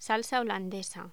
Locución: Salsa holandesa
voz
Sonidos: Voz humana